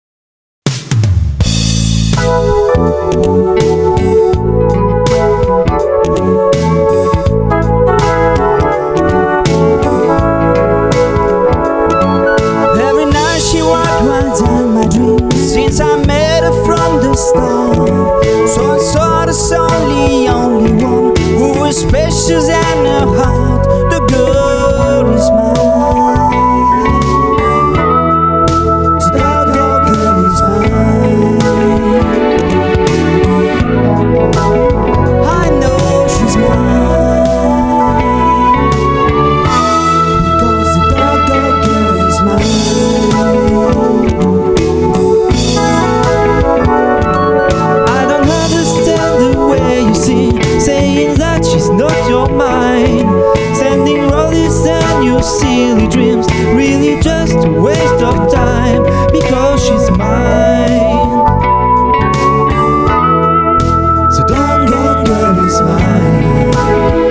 Studio Côtier, Frontignan, France.
Guitare
Chant, Choeurs
Basse